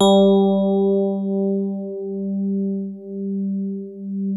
FINE HARD G2.wav